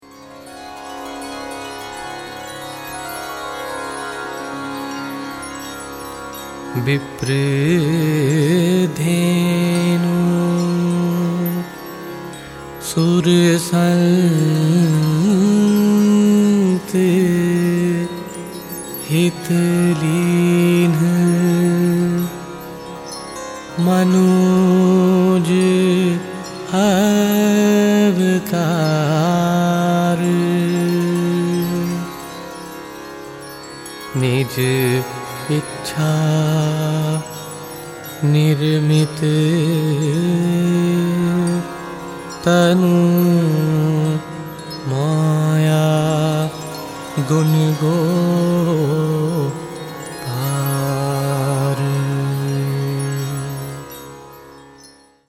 recorded in high definition 24 bit digital sound in the UK
bansuri
sarod
santoor
sitar